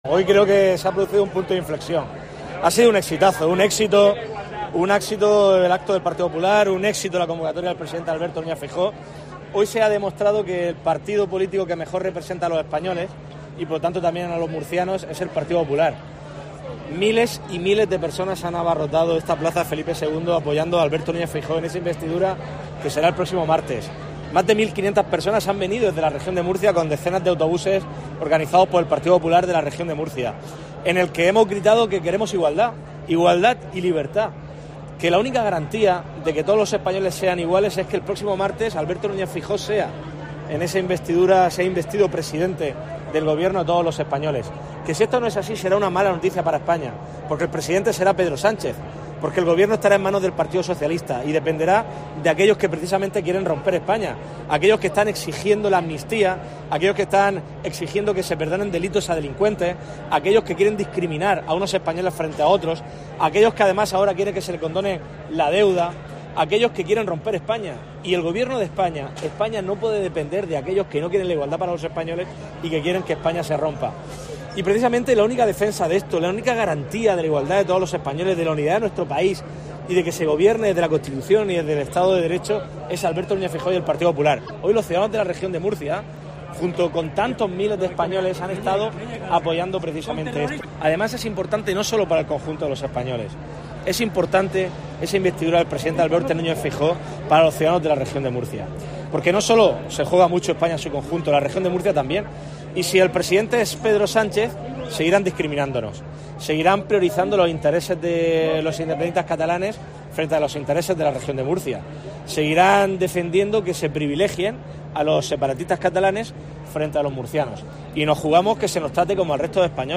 MANIFESTACIÓN
El presidente del Partido Popular de la Región de Murcia, Fernando López Miras, ha señalado este domingo desde Madrid, en el acto organizado por los 'populares' en defensa de la igualdad de todos los españoles, que "el PP es el partido que mejor representa a los españoles, frente a un PSOE que ahonda en la desigualdad".